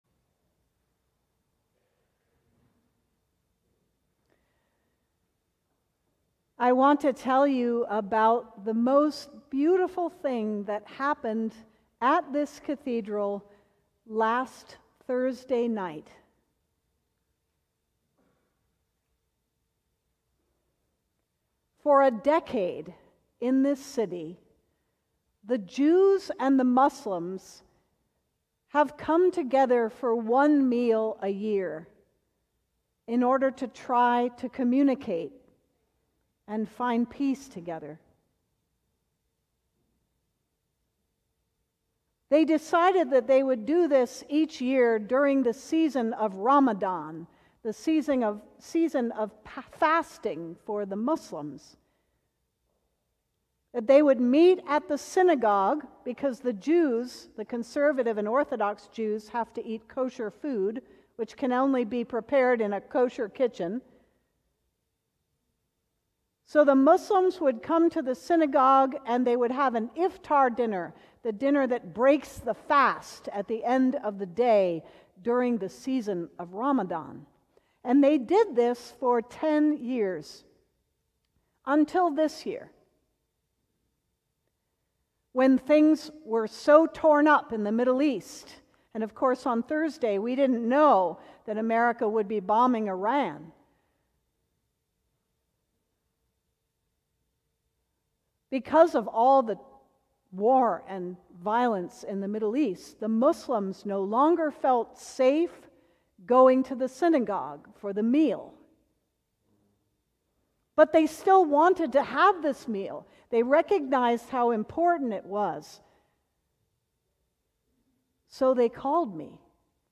Sermon: The Conversation - St. John's Cathedral